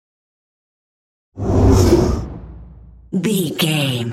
Whoosh fast trailer
Sound Effects
Fast paced
In-crescendo
Atonal
Fast
futuristic
intense